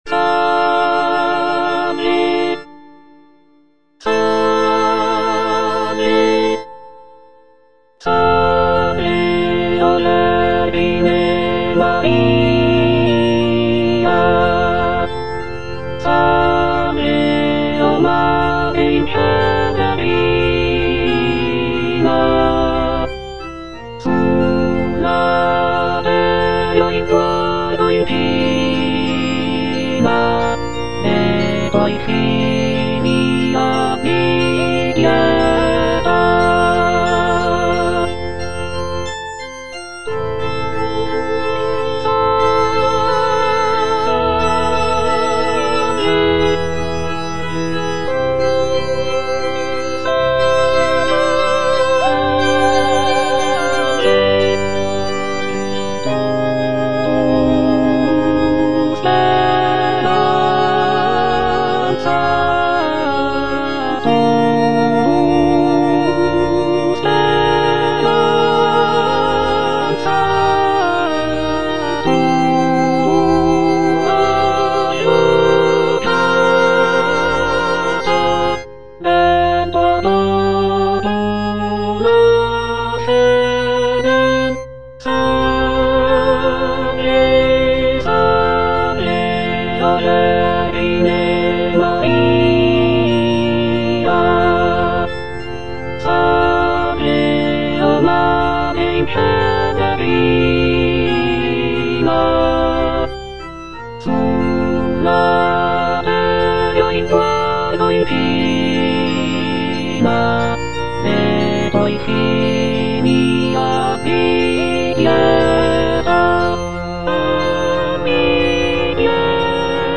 G. ROSSINI - SALVE O VERGINE MARIA Alto (Emphasised voice and other voices) Ads stop: auto-stop
"Salve o vergine Maria" is a choral piece composed by Gioachino Rossini in 1831.
The music is characterized by its serene and devotional atmosphere, with lush harmonies and expressive melodies.